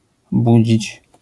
wymowa:
IPA[ˈbud͡ʑit͡ɕ], AS[buʒ́ić], zjawiska fonetyczne: zmięk., ?/i